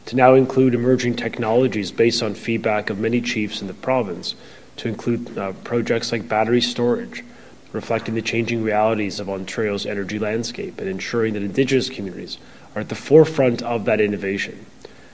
At the Mohawk Community Centre on York Road, Energy and Mines Minister Stephen Lecce laid out an increase of $10 million, bringing the total to $25 million.